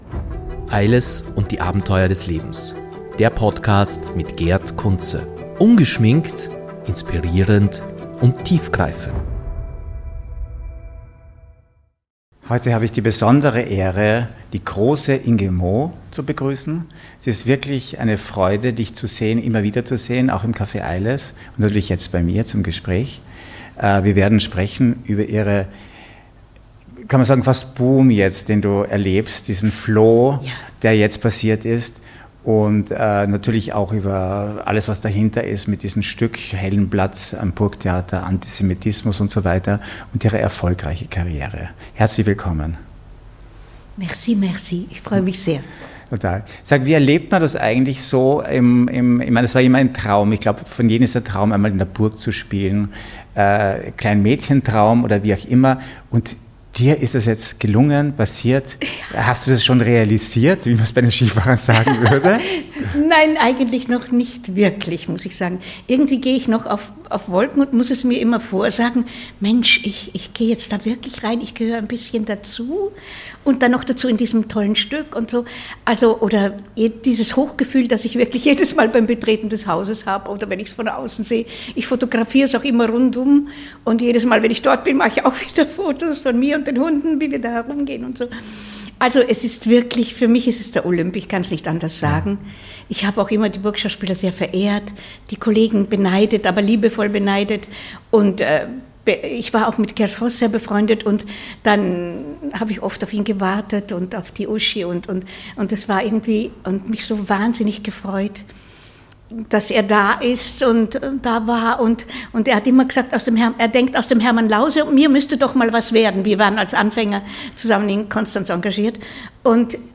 In einem tiefgreifenden Gespräch enthüllen wir die Facetten von Inges Karriere, ihre unerwarteten Erfolge und die emotionalen Höhepunkte ihrer Arbeit am Burgtheater. Erfahren Sie, wie Inge den Aufstieg zum Erfolg erlebt, die Herausforderungen des Antisemitismus meistert und in einem ständigen Prozess der Selbstentdeckung und des kreativen Ausdrucks steht.